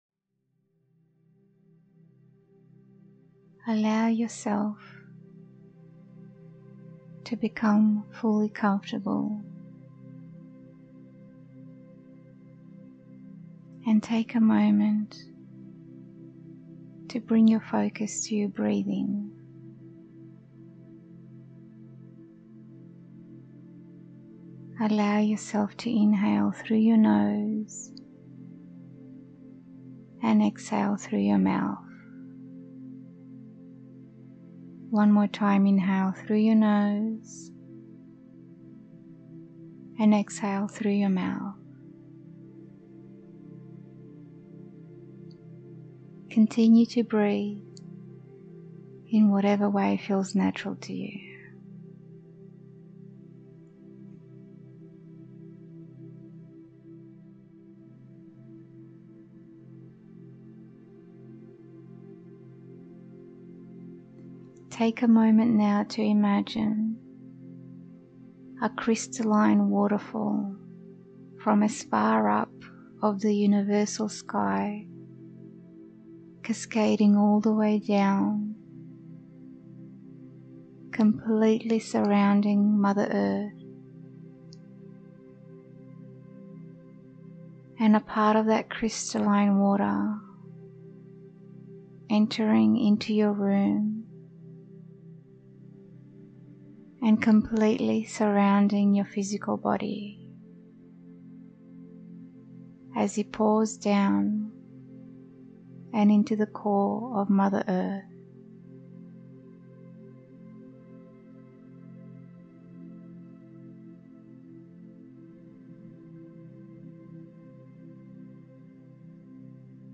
Free Guided Meditation
Free-1hr-Soul-Healing-Meditation.mp3